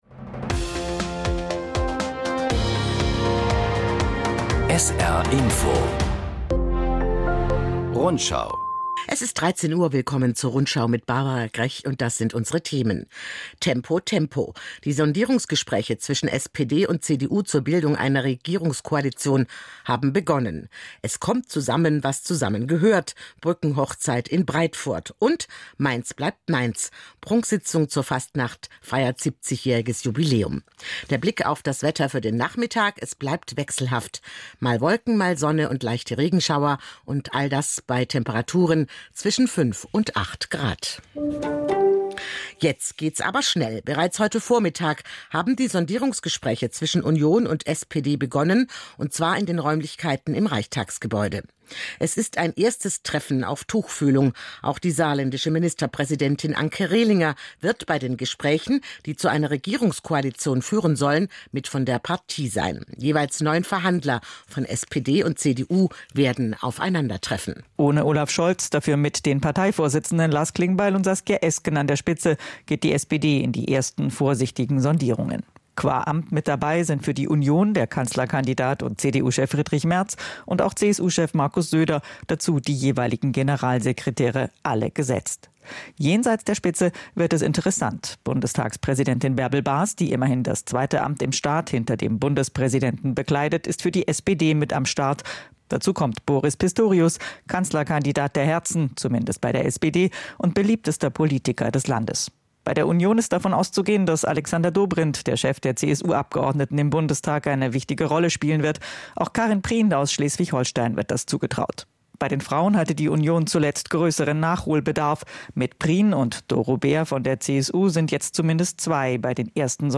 … continue reading 5 قسمت # Nachrichten